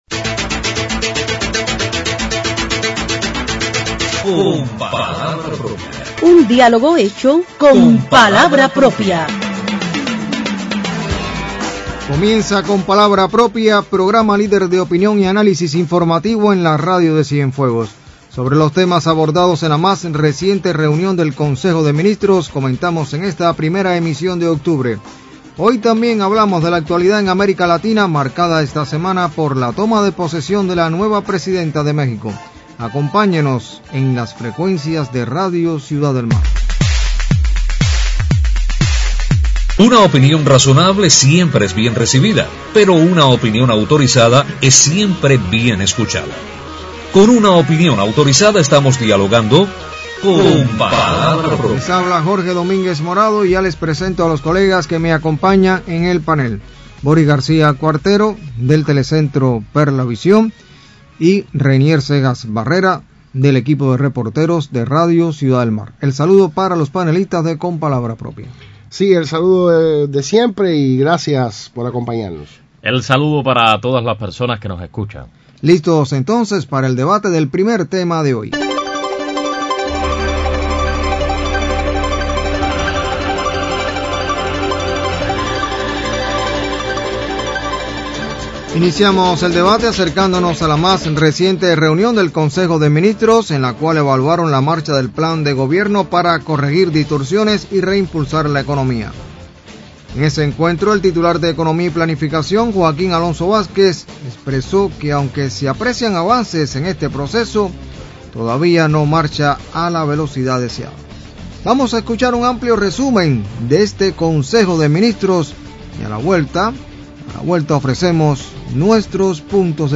Sobre los temas abordados en la más reunión del Consejo de Ministros comentan los panelistas del programa Con palabra propia en su emisión del cinco de octubre.